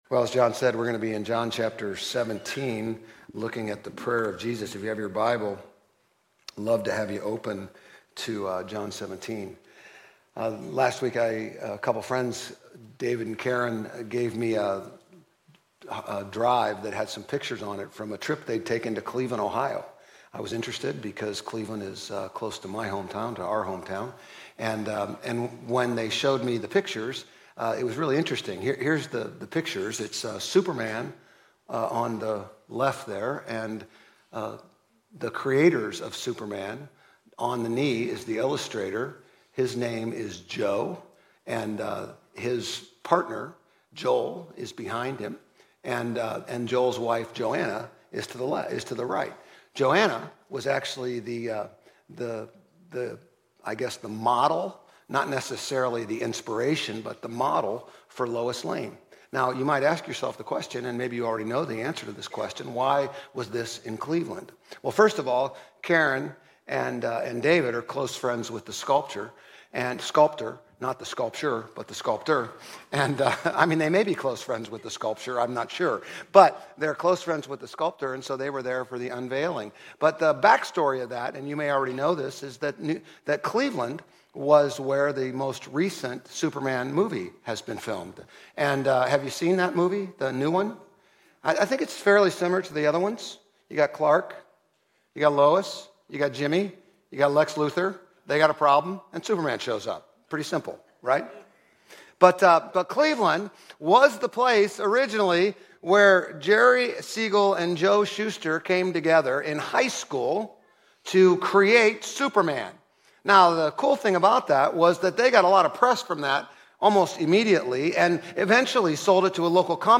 Grace Community Church Old Jacksonville Campus Sermons 8_24 Old Jacksonville Campus Aug 24 2025 | 00:32:45 1x 00:00 / 00:32:45 Subscribe Share RSS Feed Share Link Embed